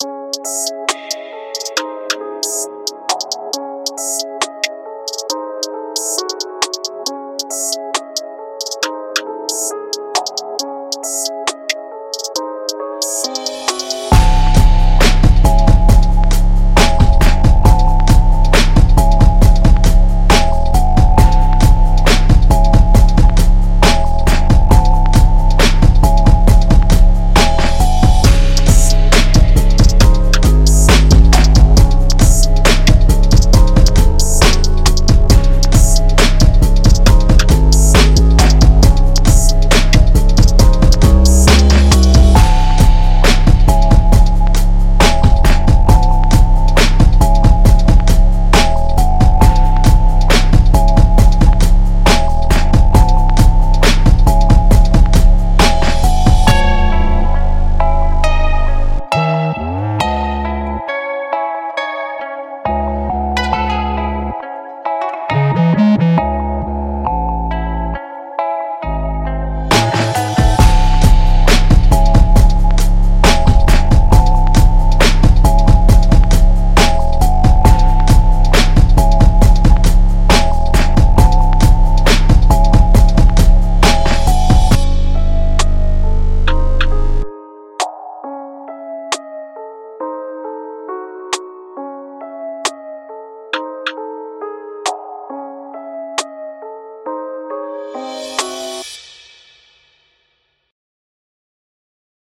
An instrumental hip-hop track at 136 BPM. A looped kick and snare forms the backbone while drill-influenced hats and percussions cut across the top. A distorted, accented 808 sits heavy in the low end. In the middle eight, a clean fingerpicked Spanish guitar enters unexpectedly before the beat returns.